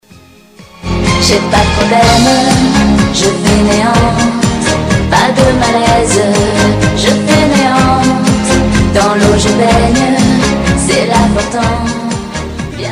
Кусочик песни